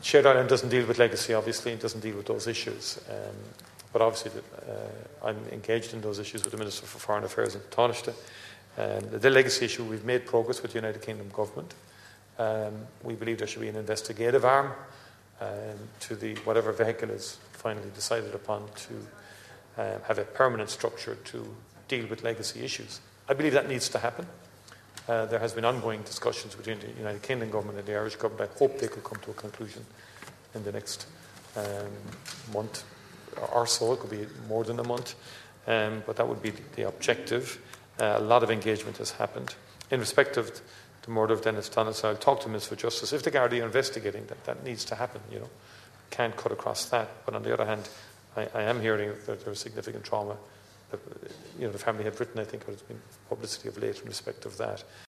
The Taoiseach says garda investigations cannot be cut across but he understands that there are special circumstances at play: